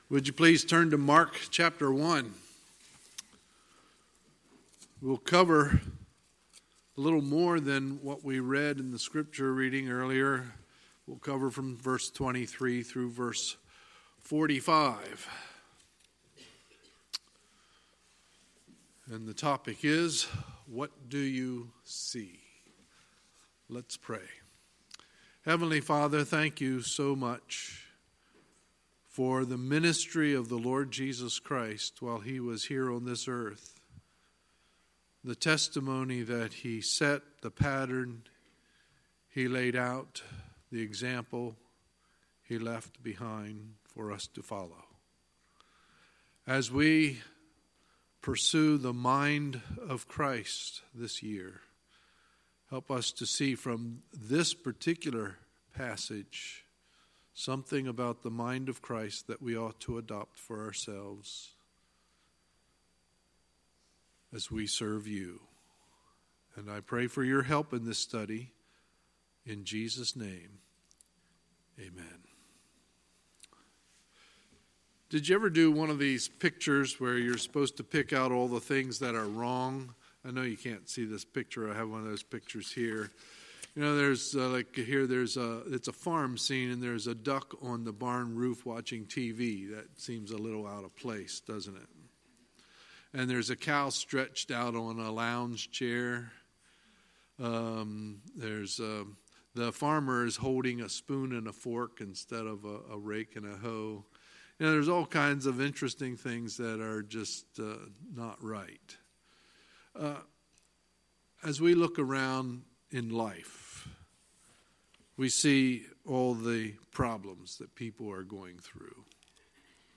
Sunday, February 10, 2019 – Sunday Morning Service
Sermons